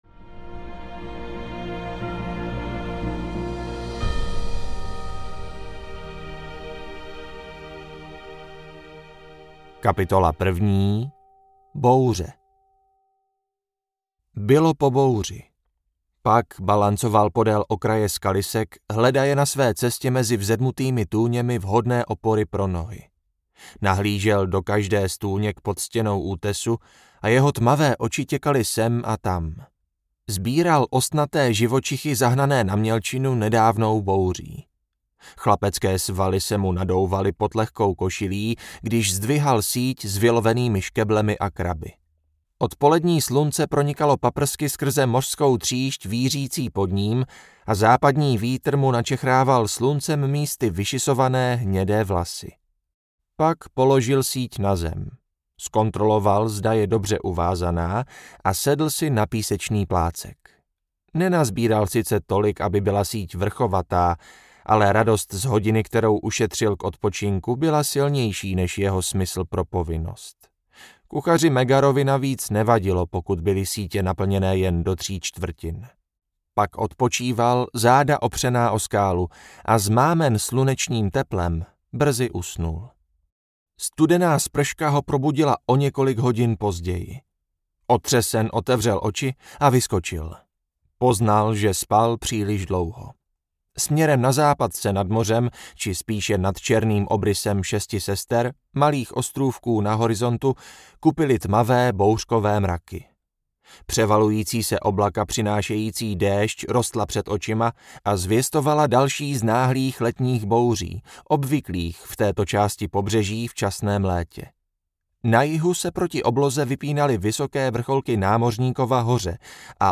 Mág: Učedník audiokniha
Ukázka z knihy